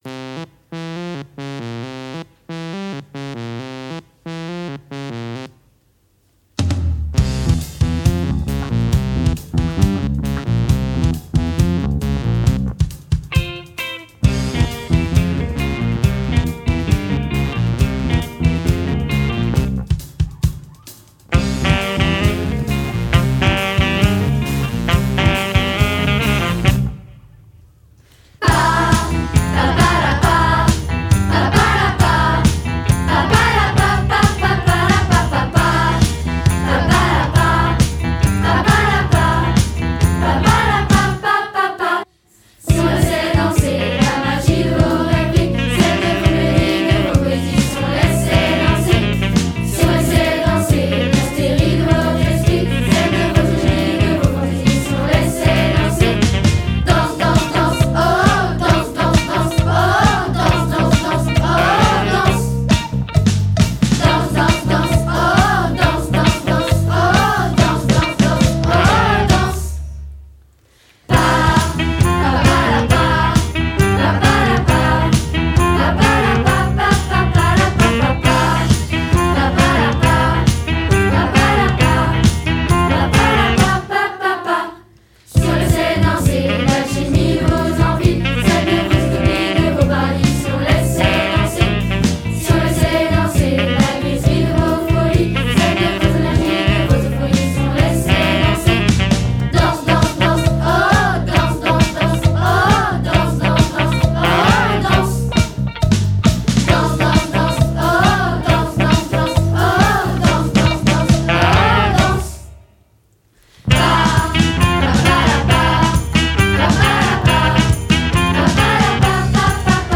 En effet, les élèves de la chorale pendant cette semaine culturelle ont enregistré 7 chansons apprises cette année, afin de réaliser un CD.